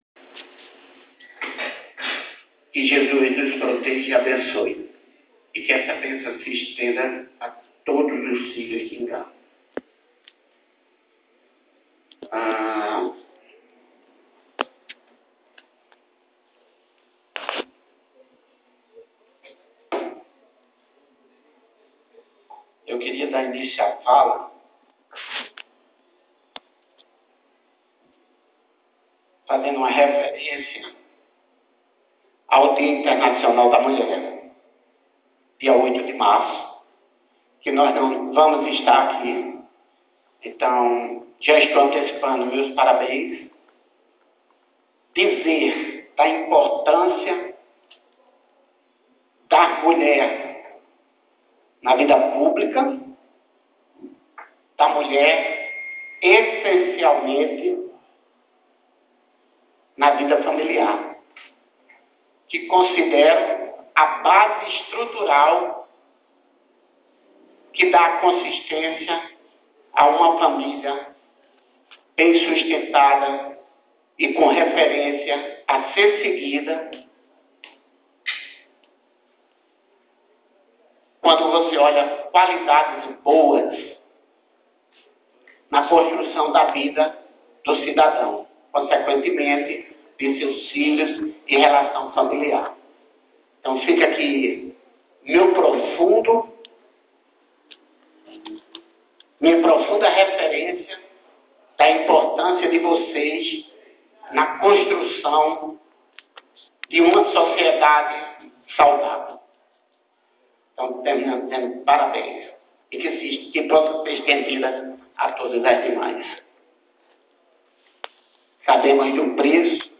Outros assuntos foram abordados no discurso do vereador Dimas Campos na sessão da última sexta-feira:
CONFIRA O DISCURSO DO VEREADOR NA ÍNTEGRA: 1 DIMAS SESSAO 6 DE MACO Comente usando o Facebook